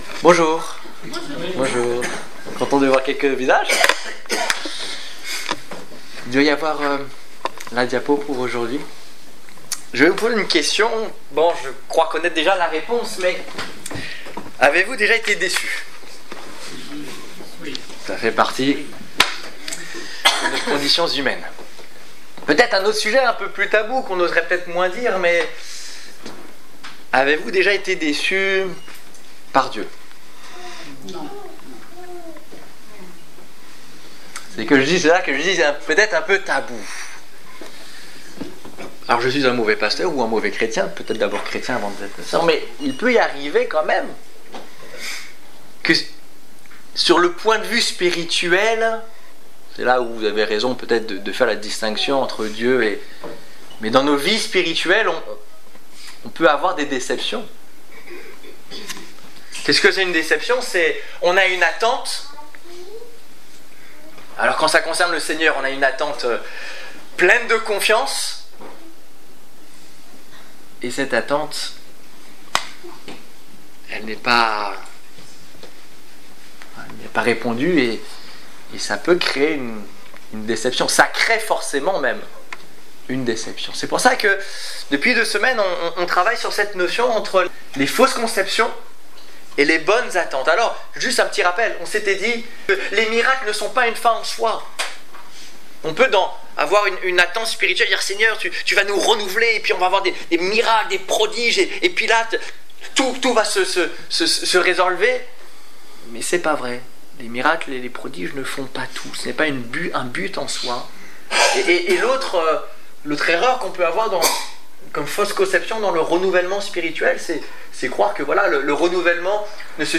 Culte du 21 décembre 2014 Ecoutez l'enregistrement de ce message à l'aide du lecteur